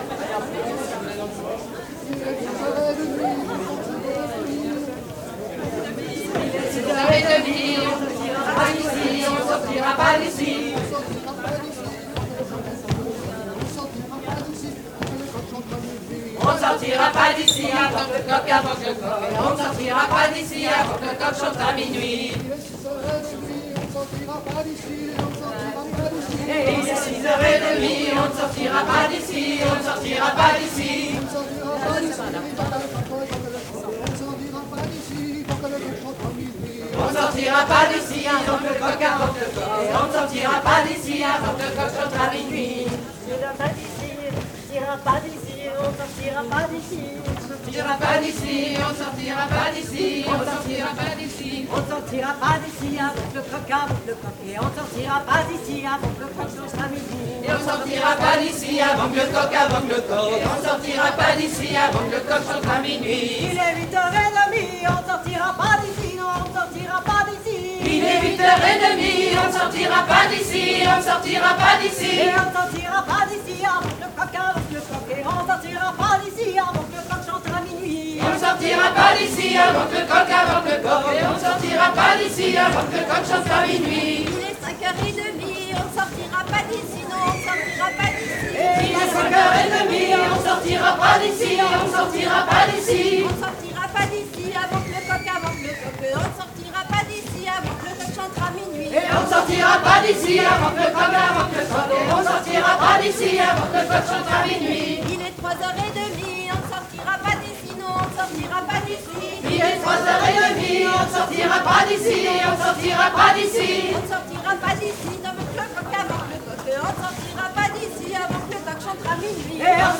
04_ridee-chant.mp3